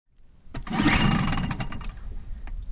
starter_boat.mp3